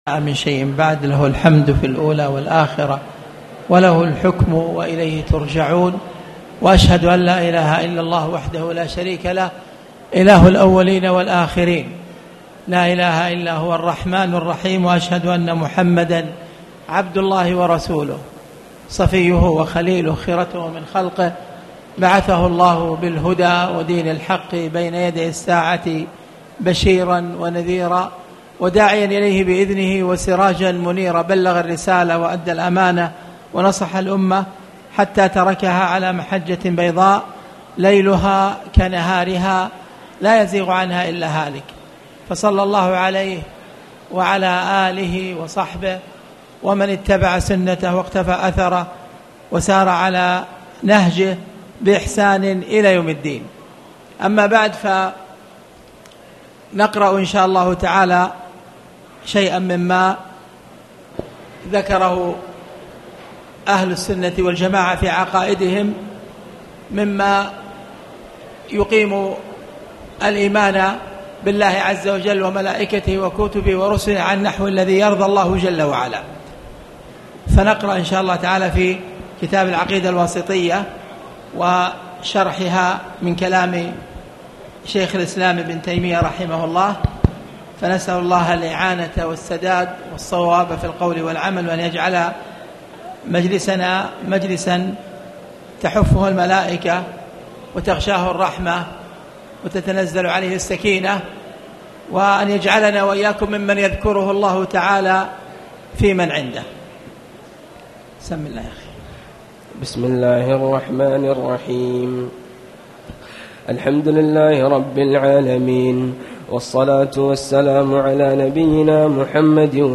تاريخ النشر ٥ ربيع الأول ١٤٣٩ هـ المكان: المسجد الحرام الشيخ: خالد بن عبدالله المصلح خالد بن عبدالله المصلح قوله تعالى: (ولا يحرفون الكلم عن مواضعه)